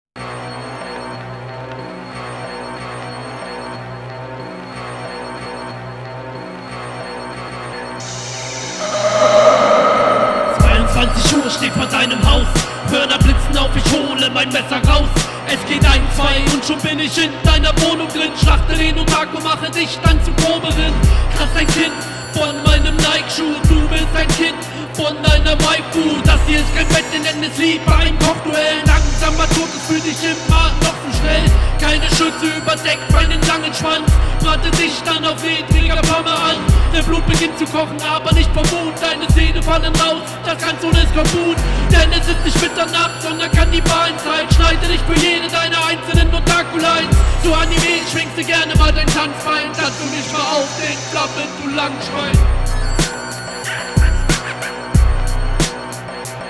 Beat ist sehr geil. Mische ist bisschen verwaschen irgendwie, ist glaub zu dumpf oder so.